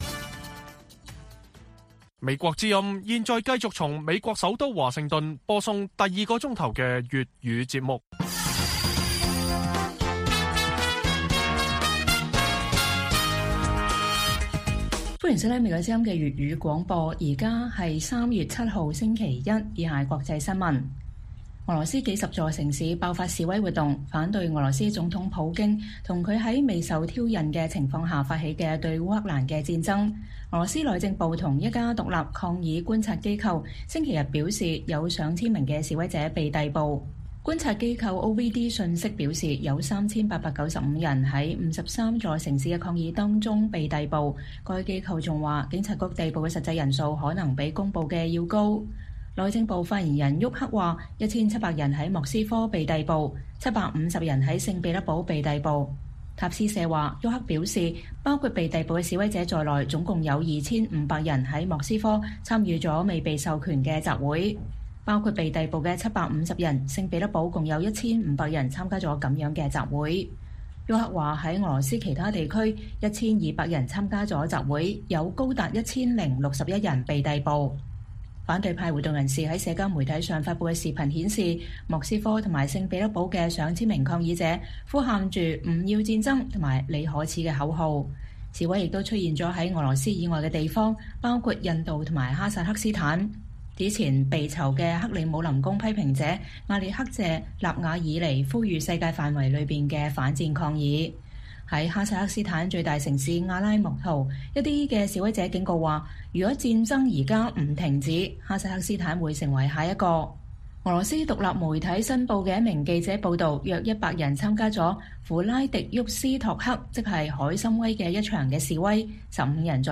粵語新聞 晚上10-11點: 俄羅斯多地爆發反戰示威上千人被逮捕